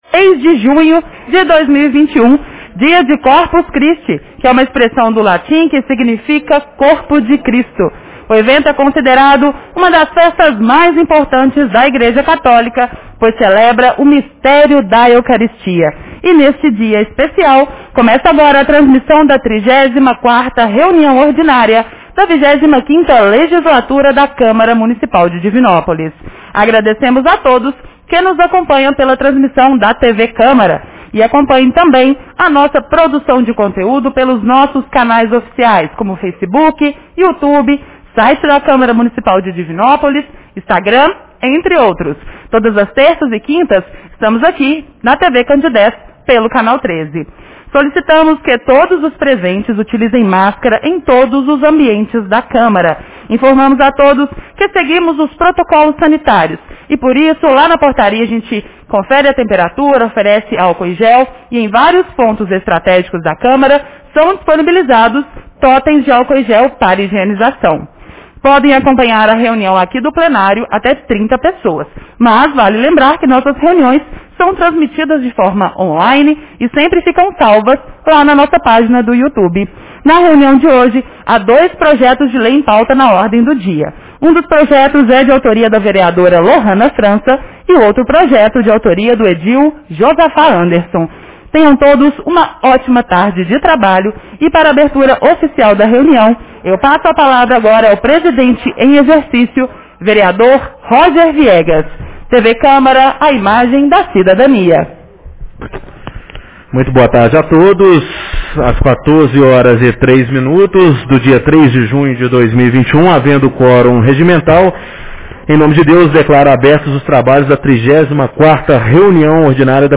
Reunião Ordinária 34 de 03 de junho 2021